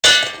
shipattach.wav